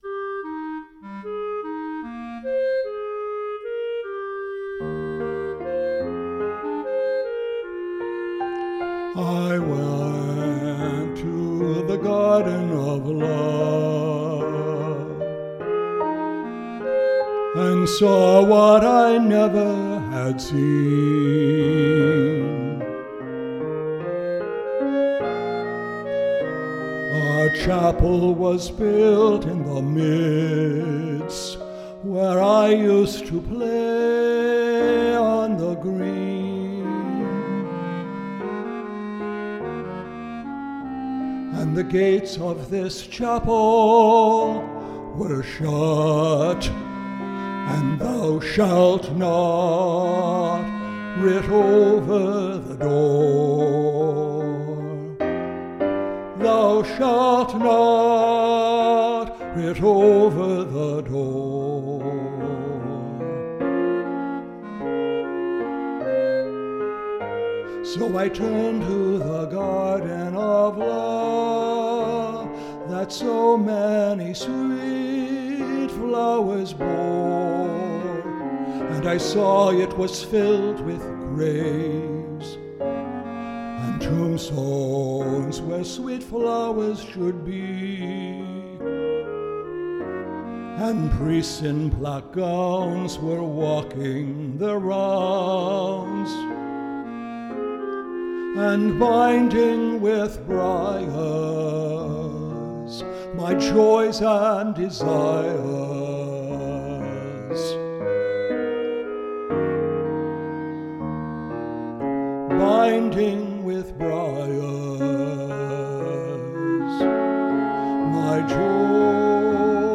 Solo voice, Bb Clarinet, and Piano